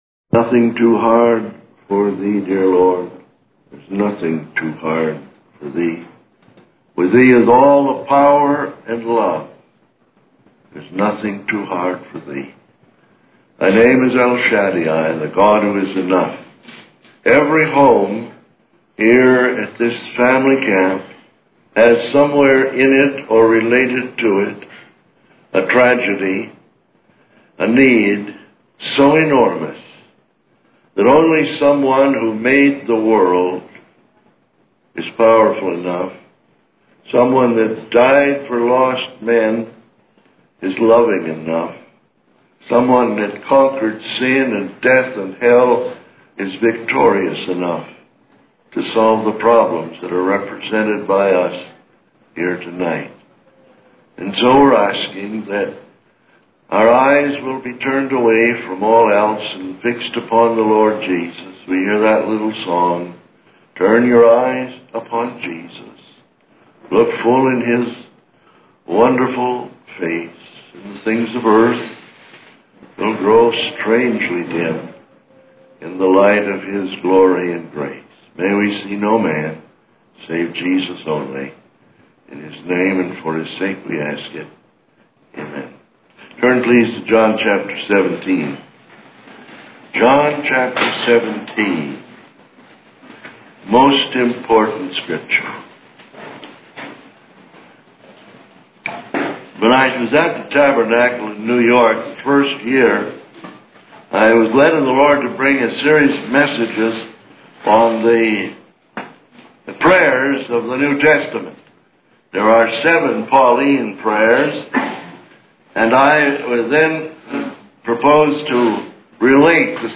In this sermon, the preacher shares a story about a mission compound during an epidemic.